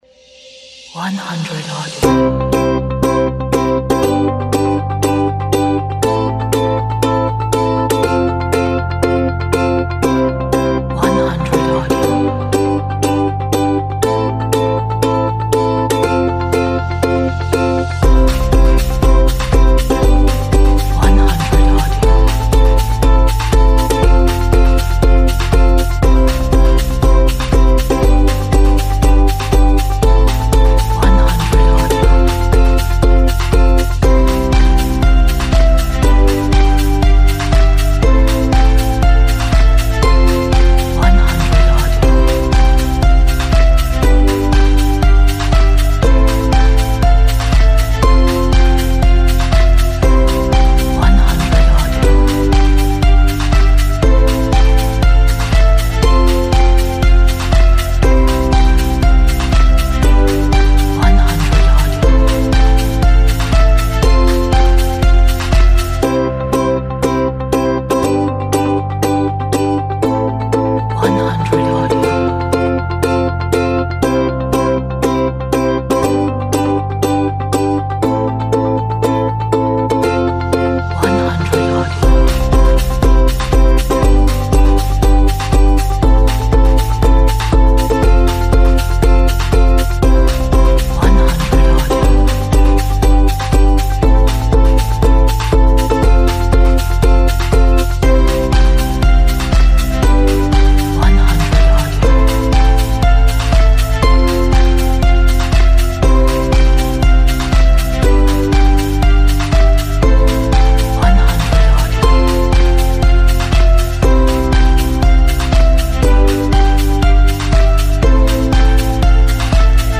a nice pop upbeat inspirational track
这是一首很好的 乐观的 鼓舞人心的流行音乐